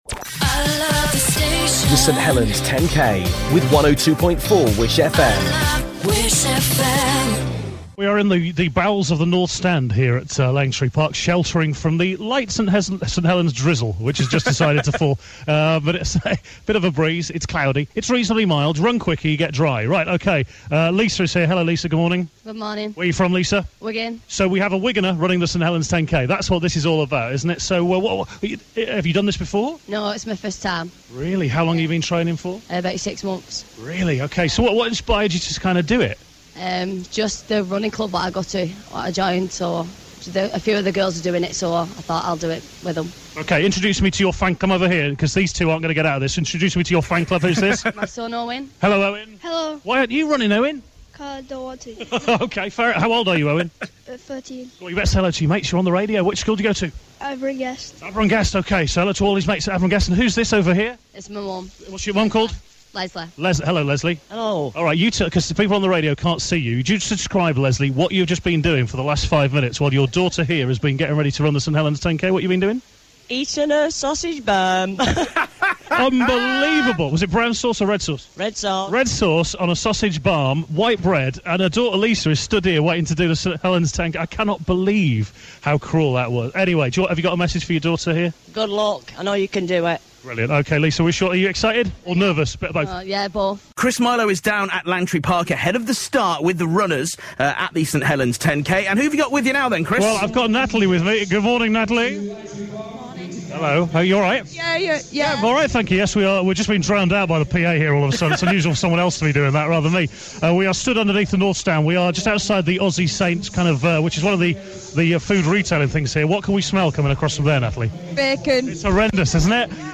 If you appeared on our live programme from Langtree Park, listen again to your big moment on the radio.